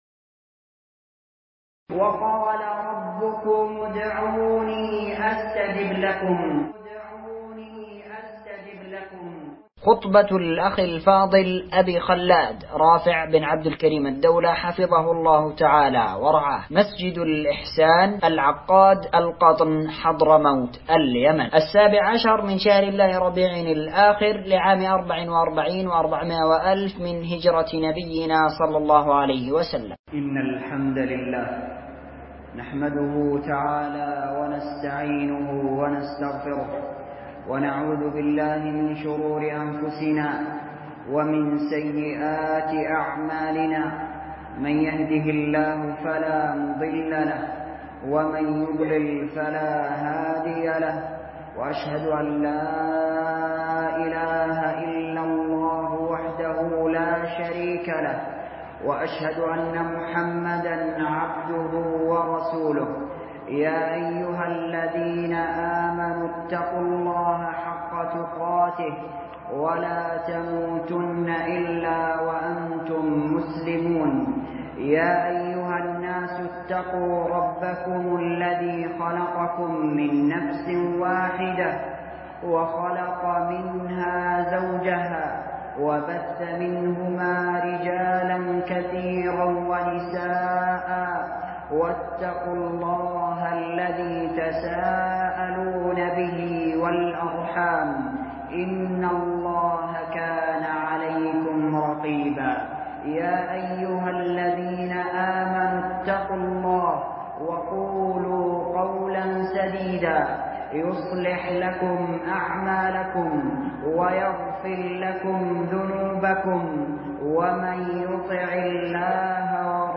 وقال ربكم ادعوني استجب لكم خطبة ← الأخ الفاضل